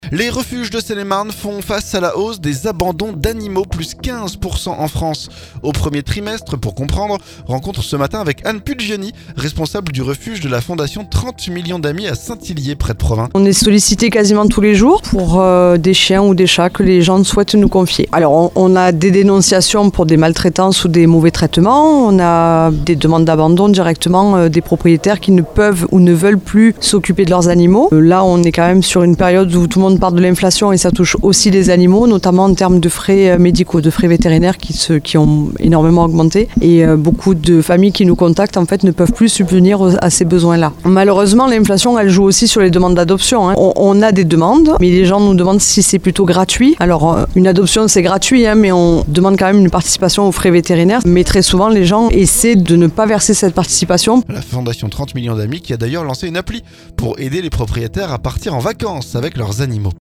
ANIMAUX - Hausse de 15% des abansons cette année, reportage dans un refuge près de Provins